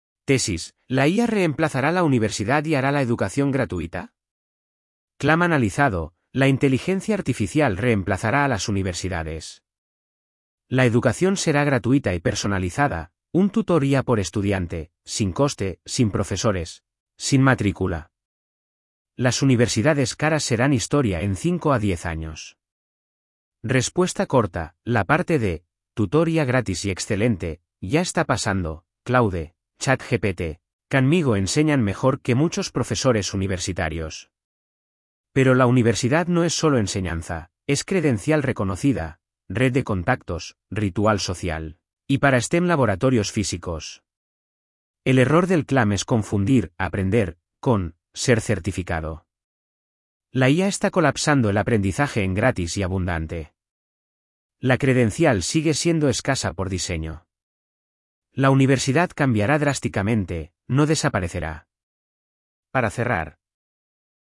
Voz: Microsoft Alvaro (es-ES, neural).